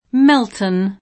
vai all'elenco alfabetico delle voci ingrandisci il carattere 100% rimpicciolisci il carattere stampa invia tramite posta elettronica codividi su Facebook Melton [ingl. m $N tën ] top. (G. B.) — con m‑ minusc., e con pn. italianizz. [ m $ lton ], come term. tessile